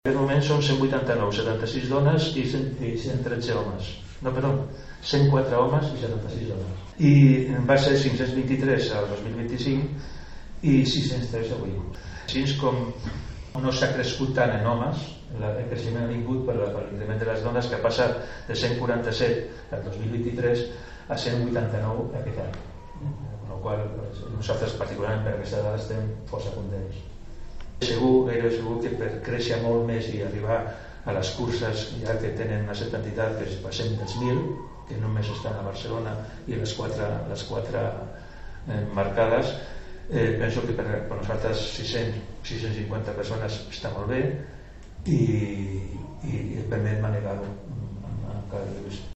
Presentada aquesta tarda a la Casa de la Vila la 26a edició de la Cursa de Martorell, una cita coorganitzada per l’Ajuntament i el Martorell Atlètic Club (MAC).